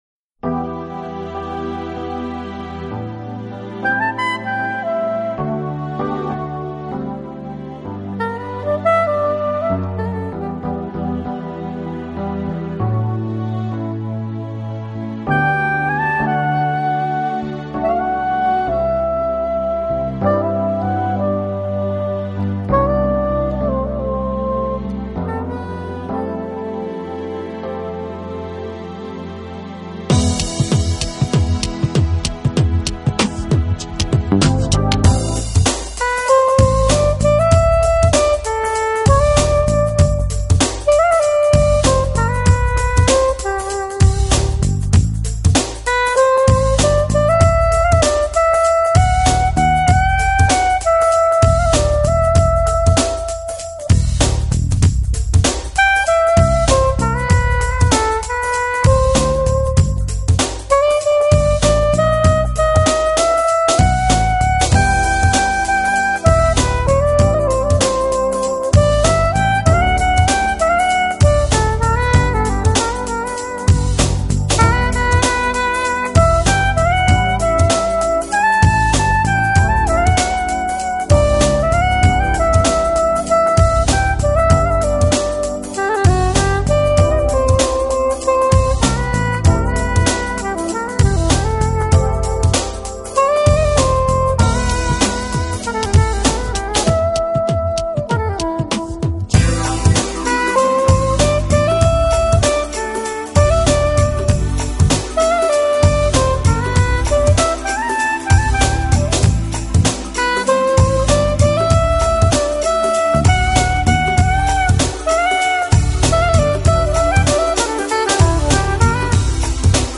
soprano saxophone, alto saxophone